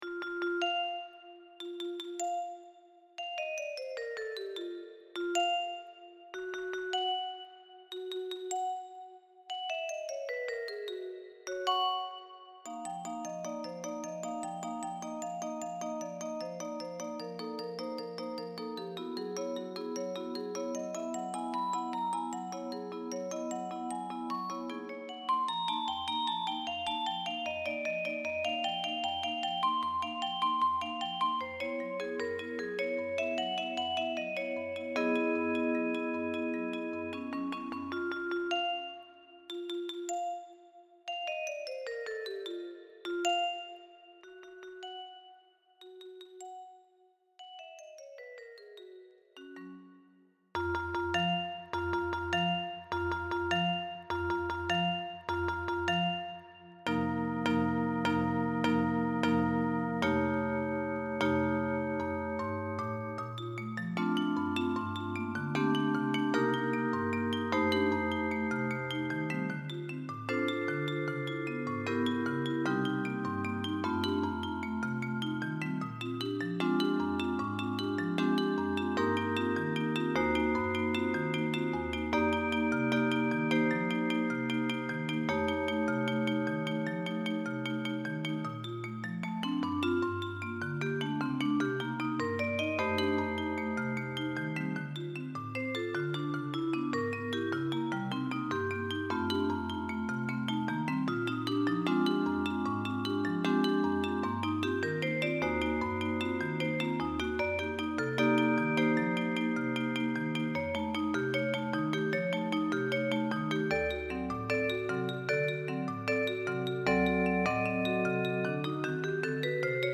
Instrumentation: percussions
classical